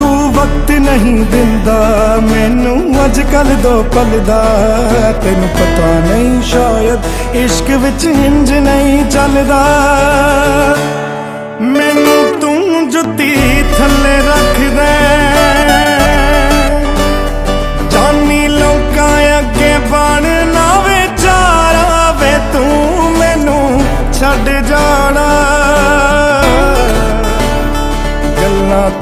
Category Punjabi